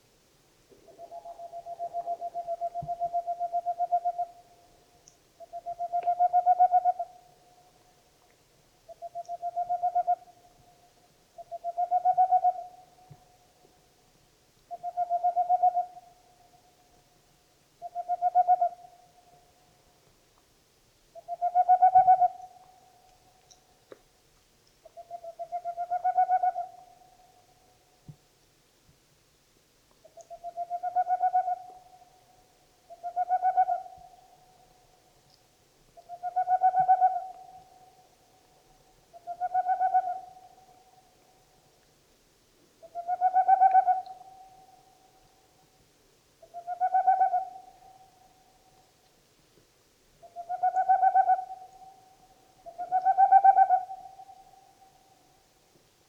Laissez vous transporter par son chant unique :
b1-chouette-de-tengmalm-aegolius-funereus-chant-typique.mp3